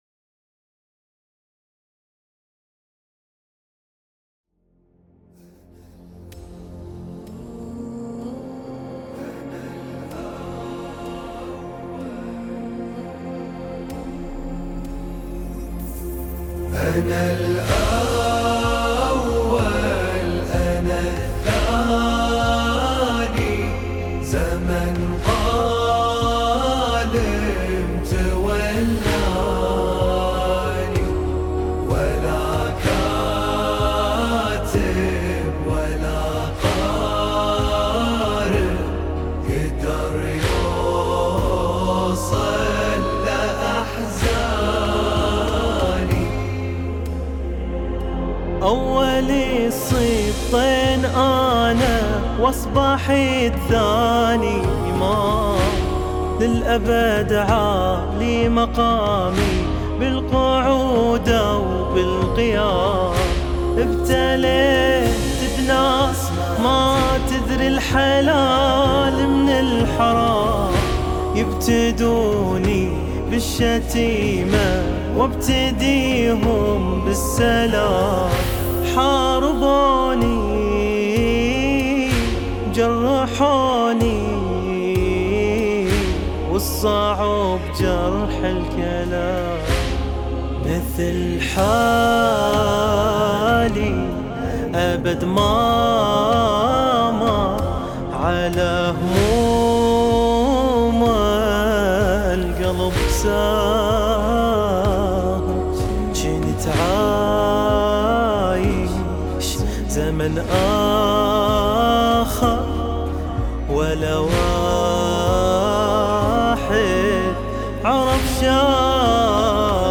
أداء الرّادود الحسيني: